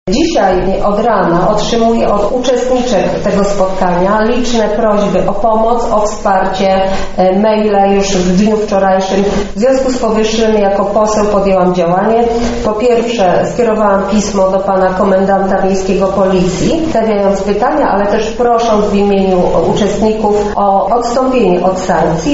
-mówi poseł Marta Wcisło.